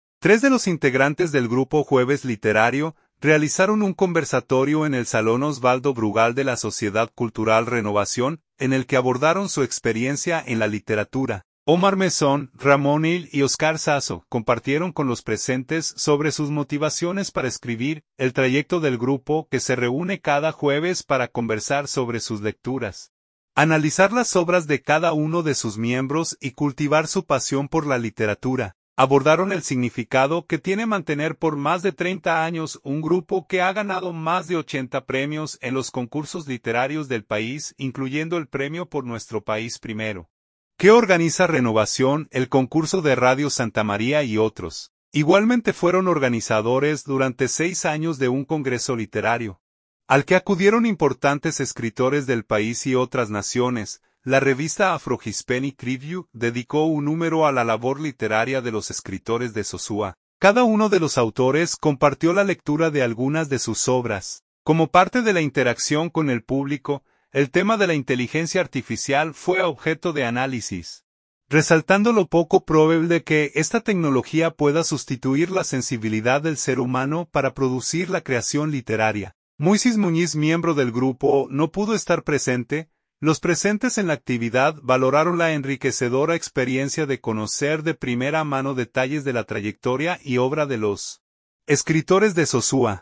Escritores de Sosúa realizan conversatorio en Renovación | El Nuevo Norte
Cada uno de los autores compartió la lectura de algunas de sus obras.